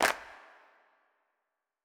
TC2 Clap5.wav